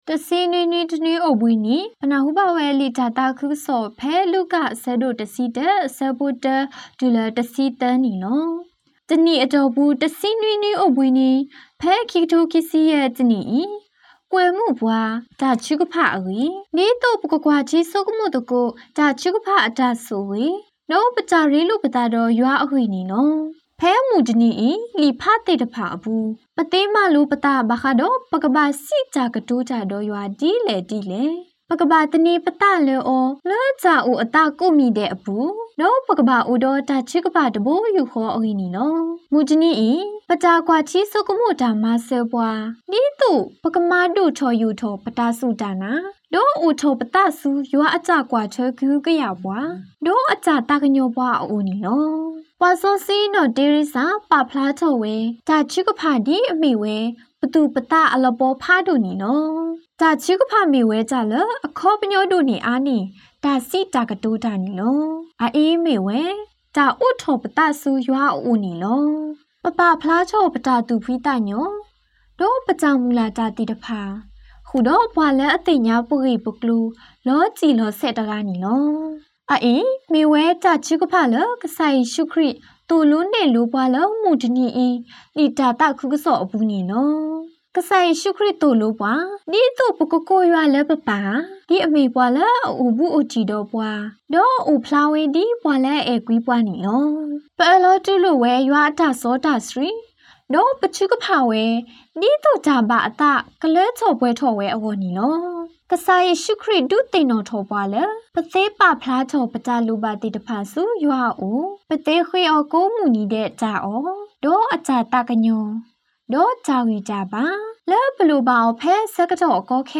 homily-17week.mp3